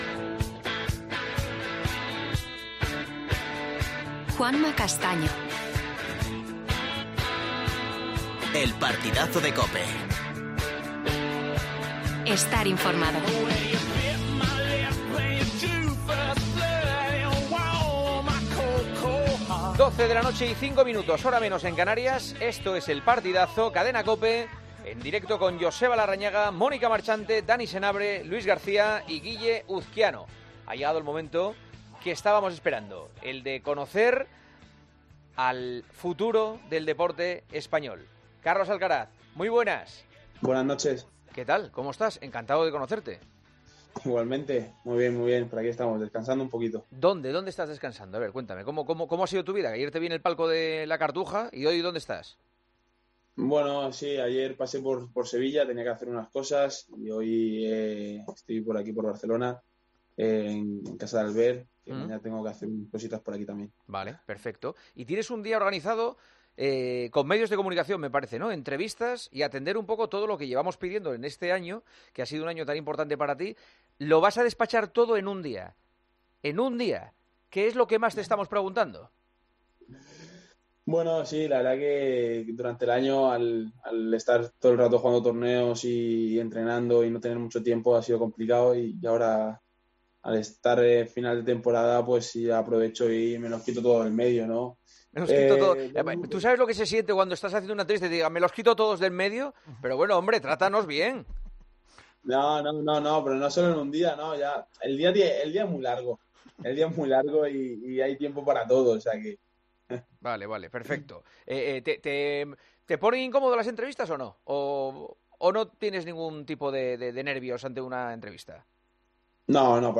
Juanma Castaño entrevista en El Partidazo de COPE al tenistas español, la gran sensación del tenis en 2021, que nos habla de cómo ve su prometedor...
AUDIO - ENTREVISTA A ALCARAZ EN EL PARTIDAZO DE COPE